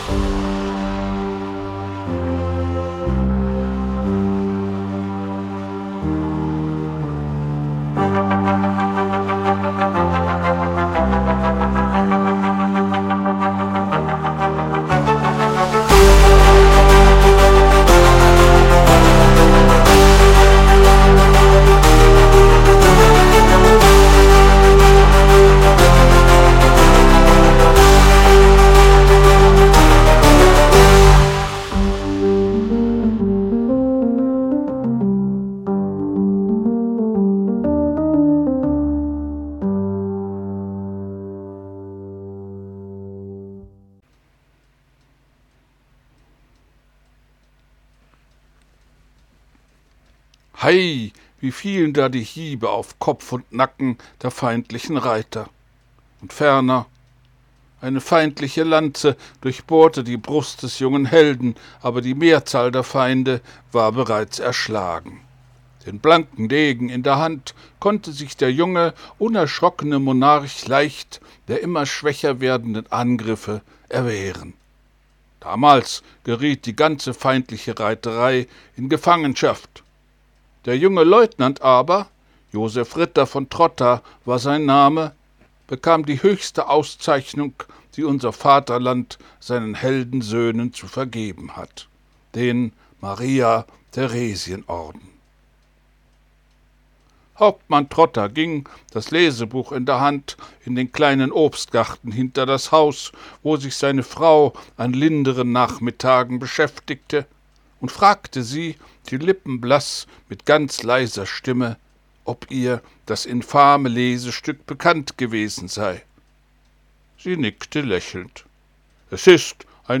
ich lese vor roth radetskymarsch 3